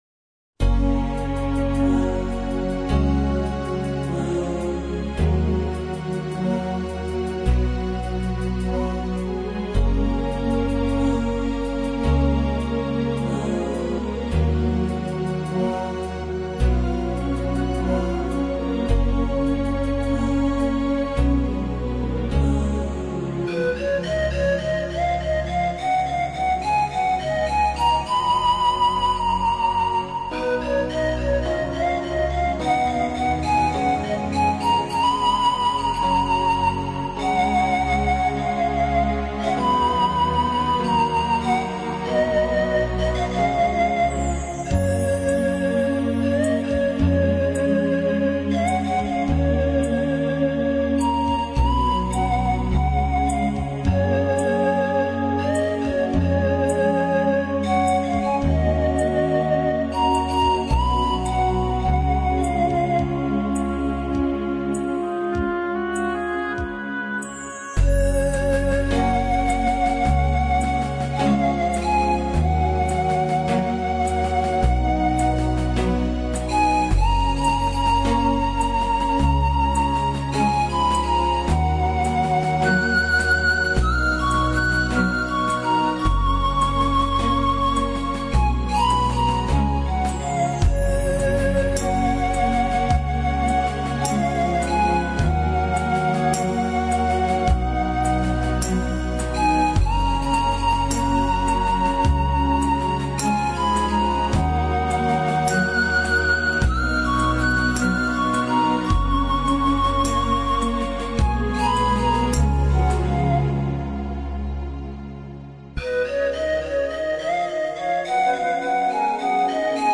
休闲音乐